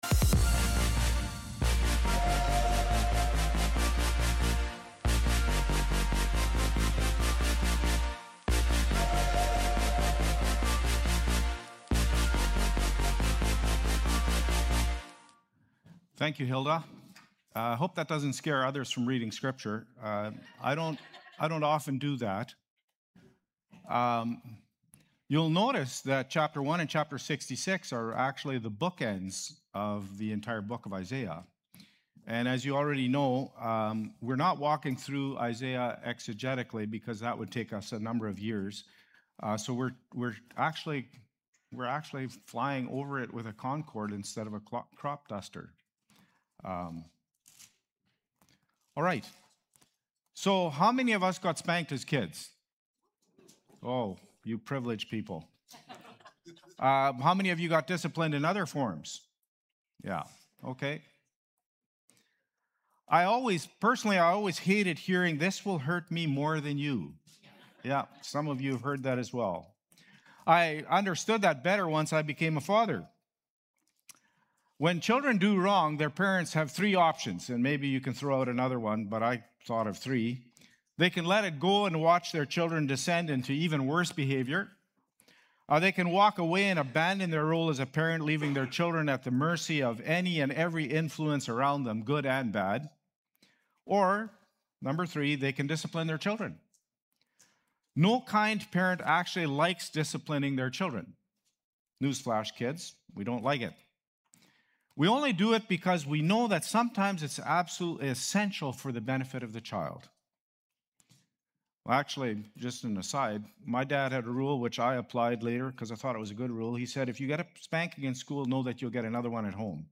Feb-1-Worship-Service.mp3